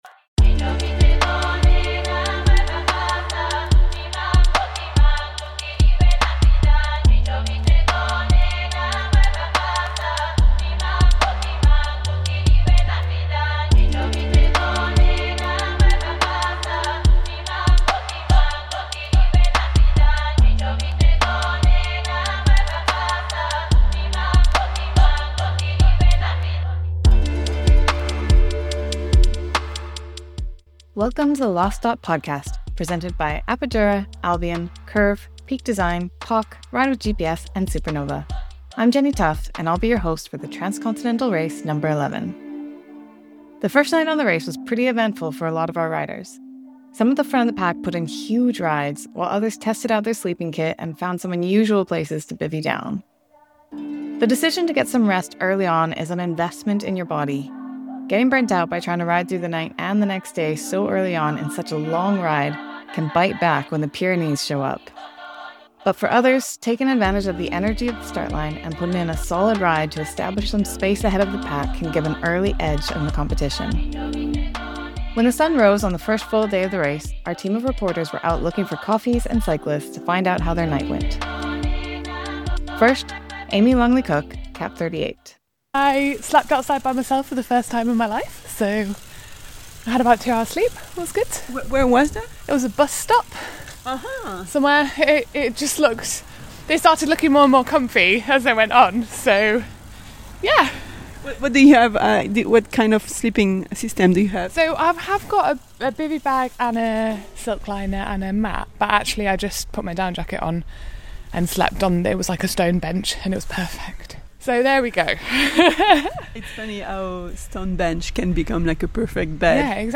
Riders share stories from their first night out: from sleeping under the stars for the very first time, to spending the night in a graveyard toilet. A TCR birthday is celebrated, and Spain’s rich supply of food and drink is keeping morale high. As the top-ten riders reach Control Point 1, the media team is there to capture the sounds of their breathless arrivals.